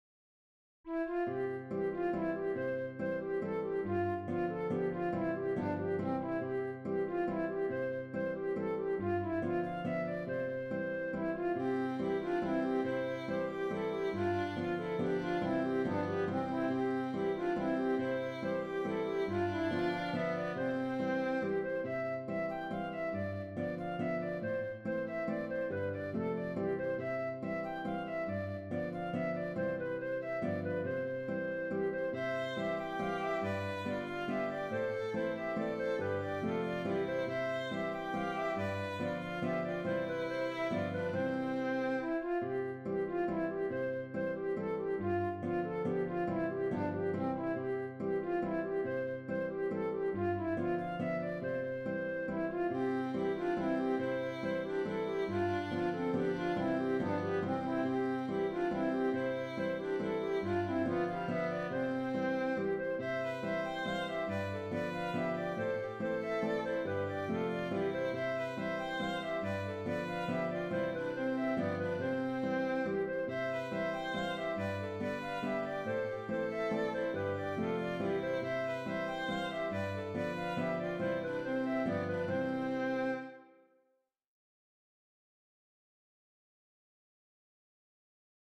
Mazurka
Trad. Irlande
J’en ai fait deux contrechants.